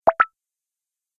bubble-pop-389501.ogg